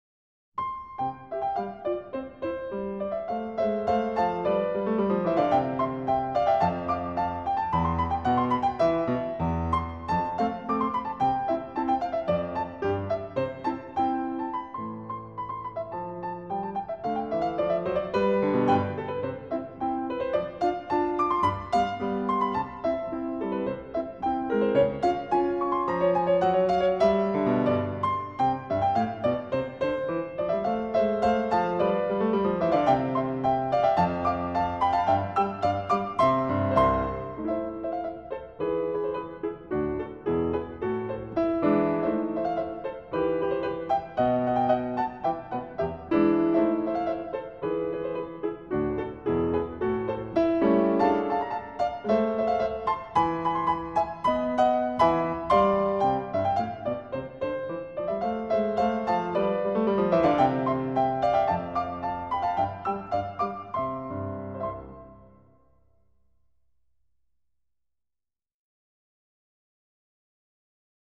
0203-钢琴名曲加沃特舞曲.mp3